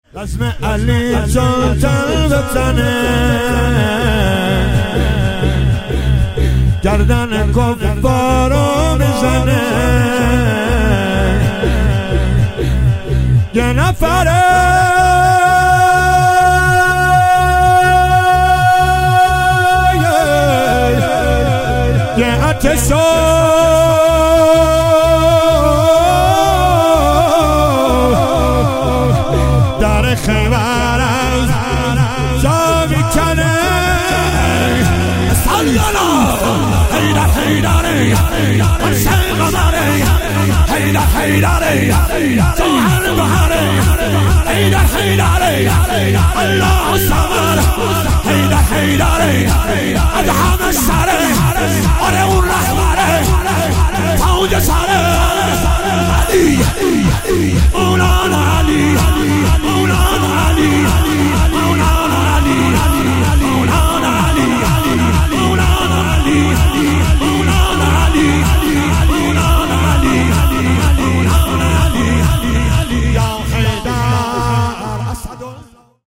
مداحی
هیئت محبان الرقیه(س) شهر ری